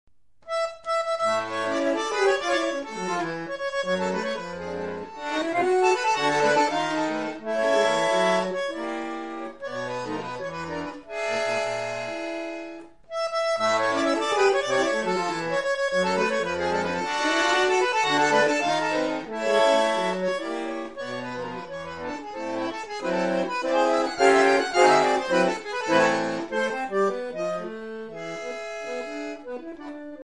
Bandoneon
Milonga